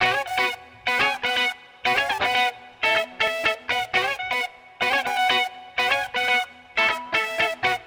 Also below in a faux Ableton-style interface are all the original loops used to create the tracks.
jangles.wav